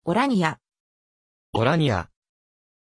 Pronunciation of Orania
pronunciation-orania-ja.mp3